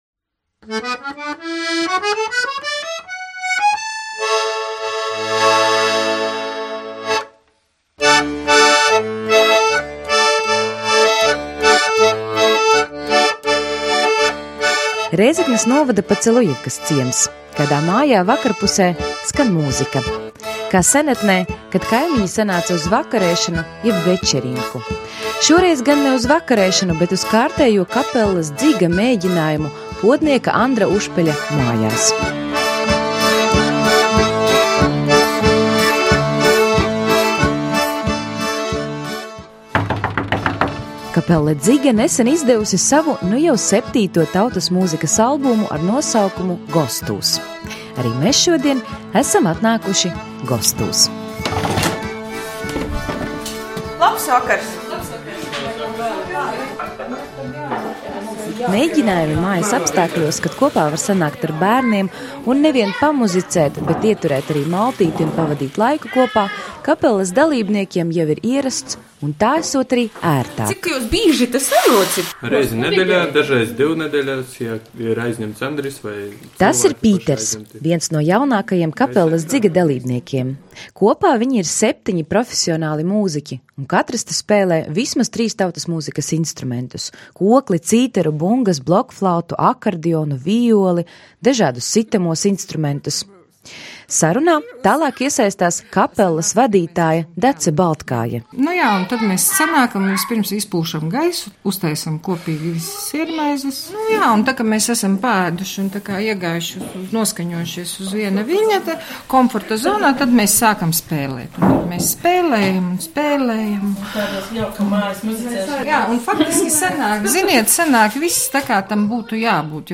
Rēzeknes kapelas “Dziga” daiļradē šis ir jau septītais tautas mūzikas albums, kurā šoreiz iekļautas vienpadsmit latviešu, latgaliešu un dažu citu Eiropas valstu tautu kadriļu melodijas.
Viņu jaunākais albums “Gostūs” šodien piedzīvo savu ieskandināšanu, bet viesos pie kapelas pirms kāda laika bija raidījuma “Kolnasāta” veidotāji.